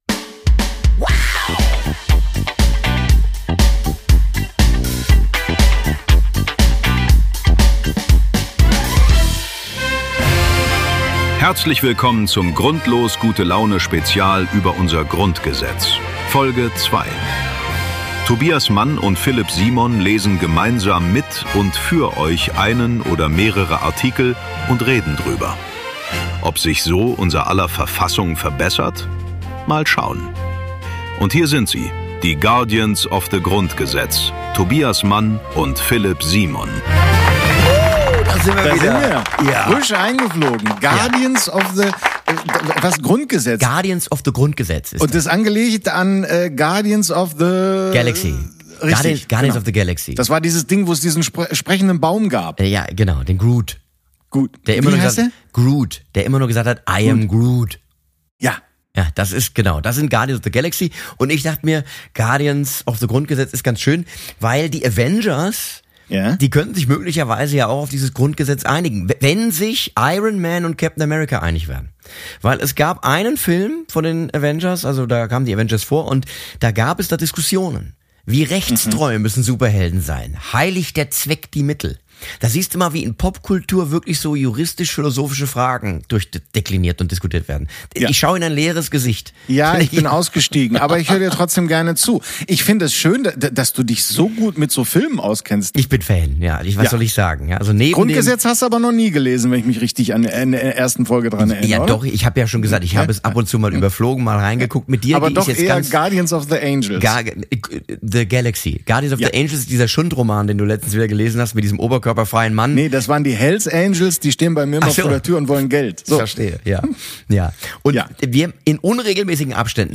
Tobias Mann und Philip Simon lesen das komplette Grundgesetz! Die beiden Restdemokraten wollen verstehen, analysieren, besprechen und zelebrieren.